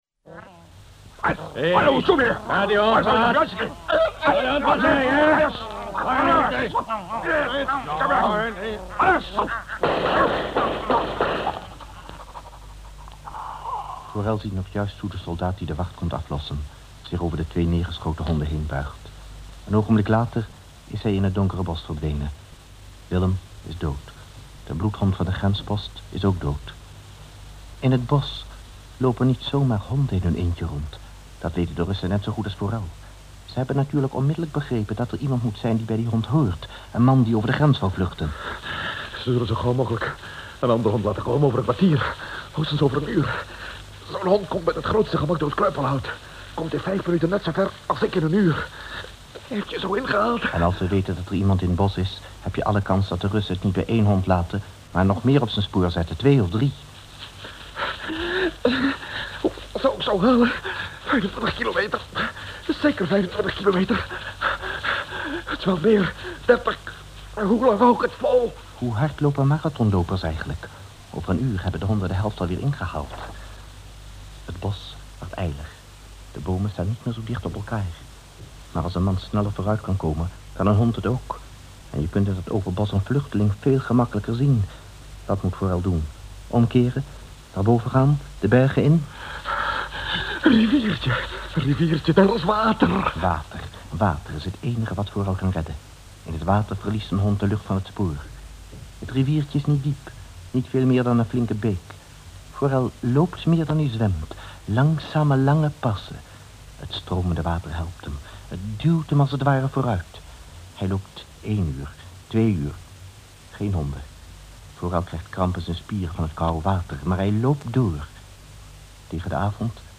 Dit 8-delige hoorspel (hier in 7 delen samengevat) duurt in totaal ongeveer 5 uur.
Verder is dit hoorspel niet zo heel goed bewaard gebleven.